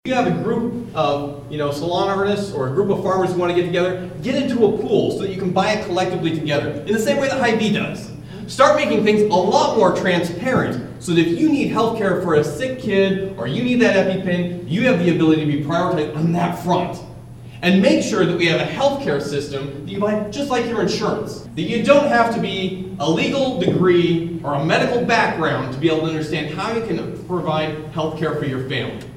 Republican House & Senate candidates speak in Atlantic Monday evening
(Atlantic, Iowa) – Cass County Republicans hosted a “Know Your Candidates” forum Monday evening, at the Cass County Community Center in Atlantic.